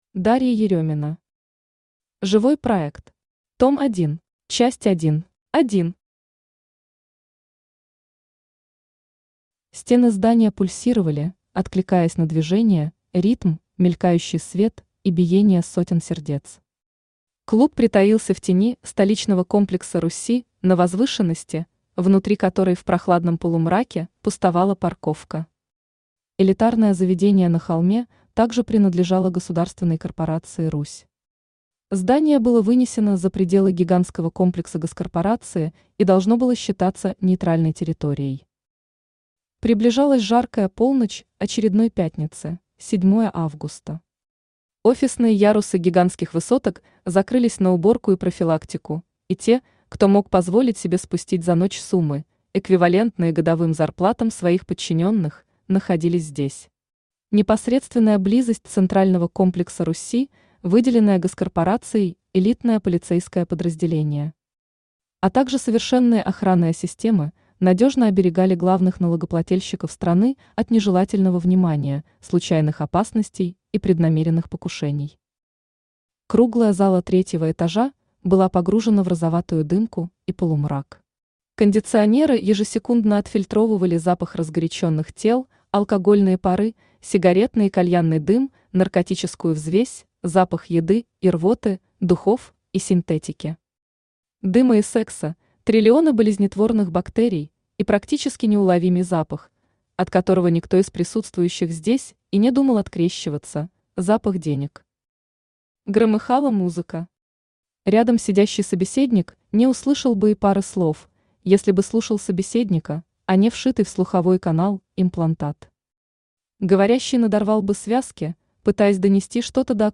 Аудиокнига Живой проект. Том 1 | Библиотека аудиокниг
Том 1 Автор Дарья Викторовна Еремина Читает аудиокнигу Авточтец ЛитРес.